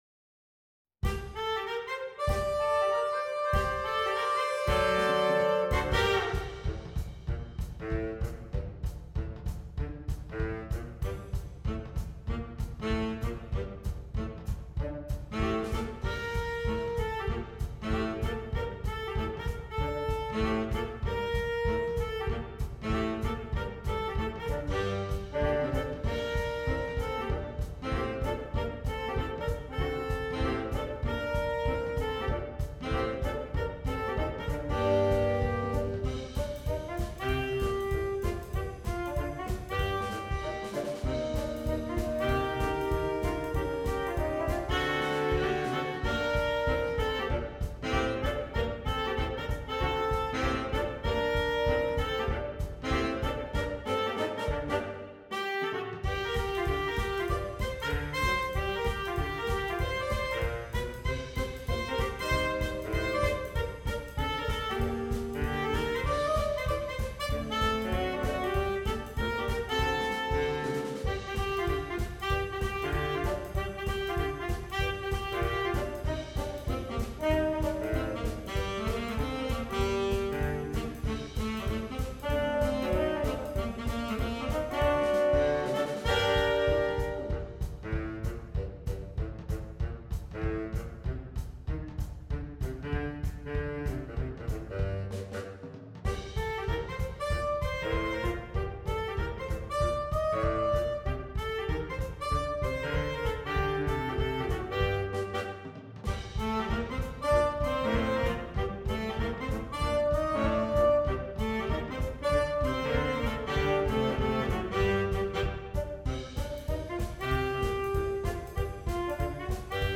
Saxophone Quartet (AATB)
a jump swing chart